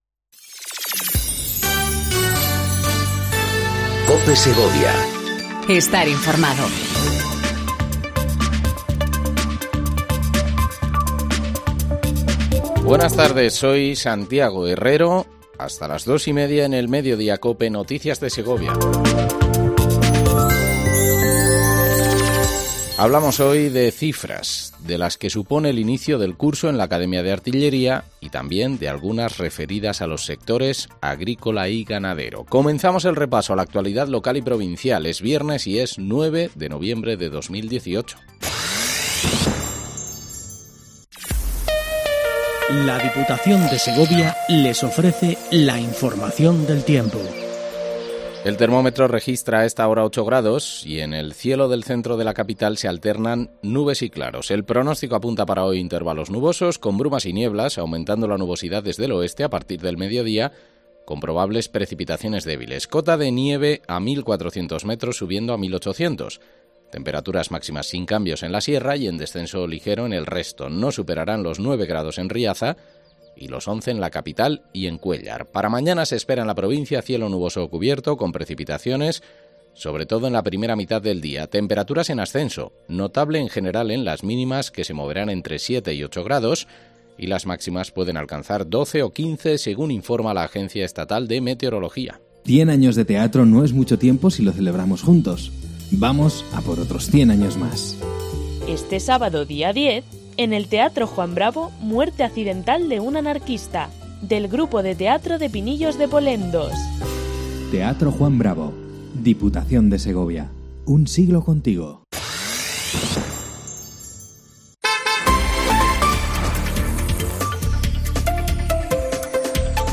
INFORMATIVO MEDIODÍA COPE SEGOVIA 14:20 DEL 09/11/18